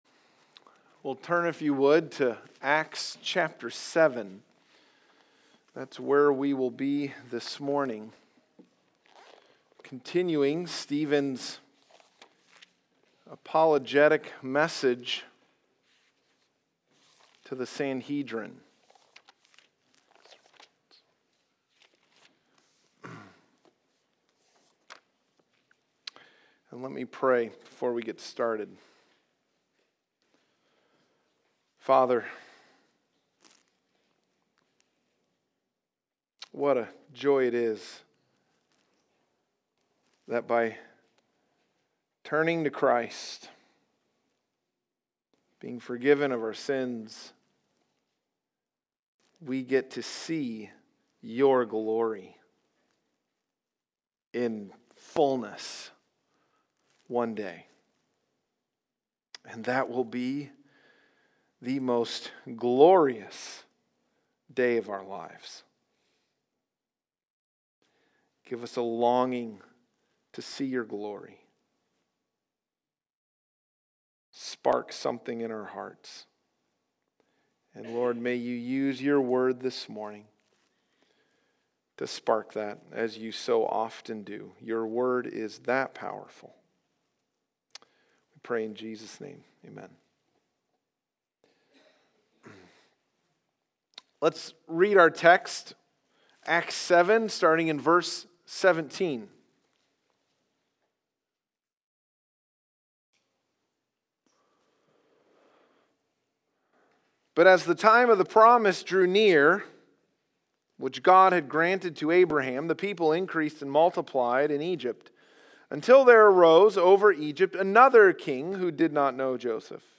Stephen’s Martyrdom Sermon (Part 3)